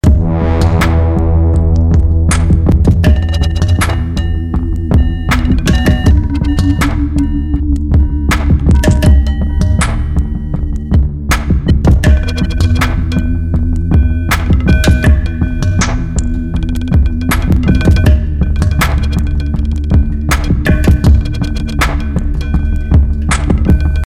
Zusammen mit den anderen Instrumenten: